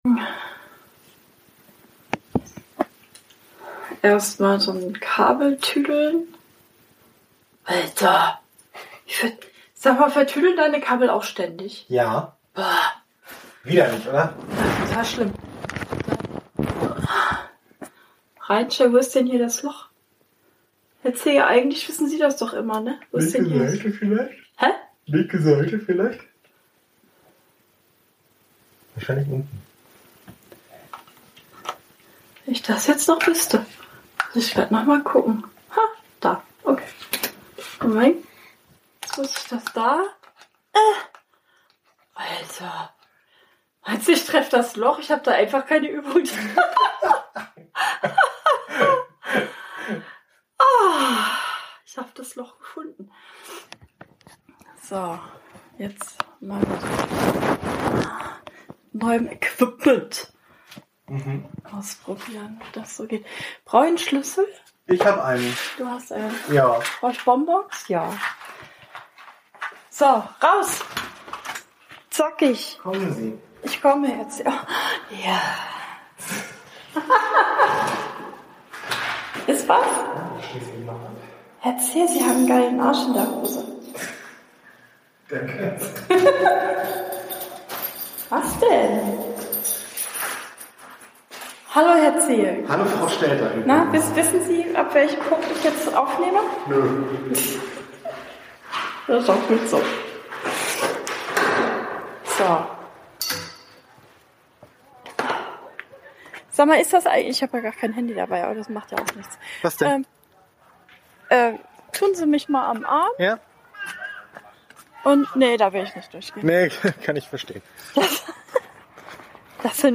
alle werden schlanker und am Schluss niest jemand.